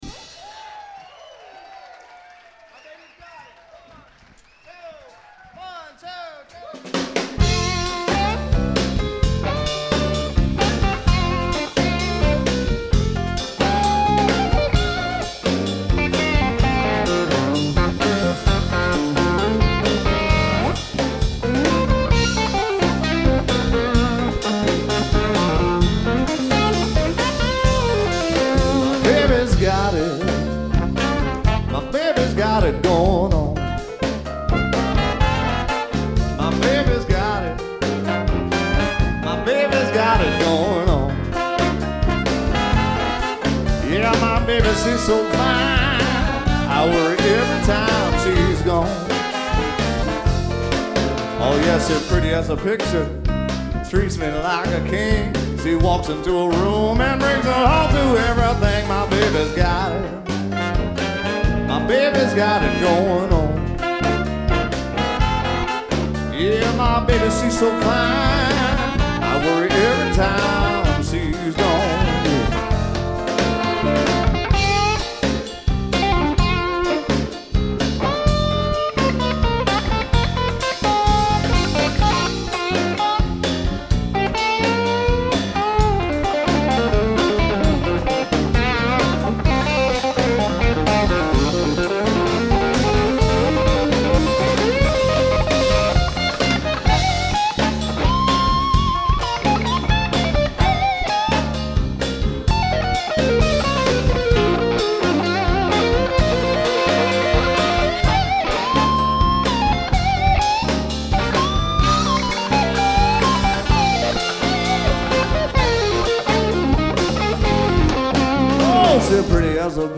Blues guitarist and vocalist